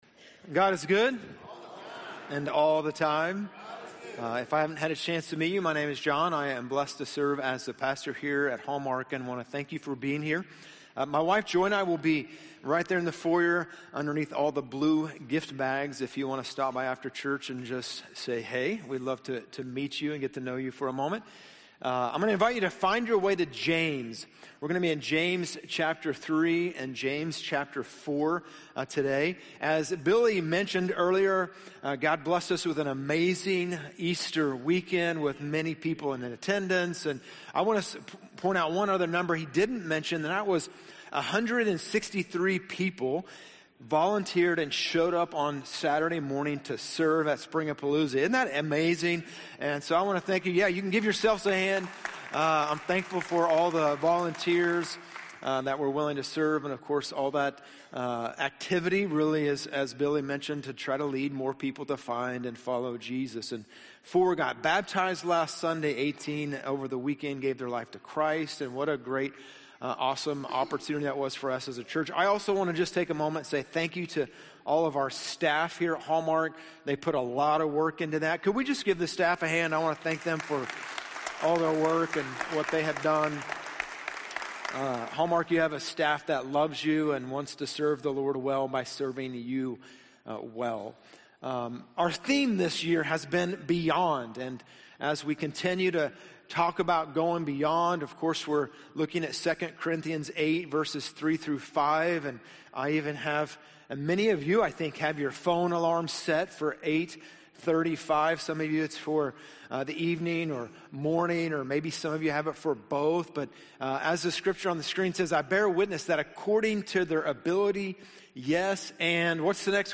Missing Peace? #2 - 3 Rules to Making Peace with Others - Sermons - Hallmark Church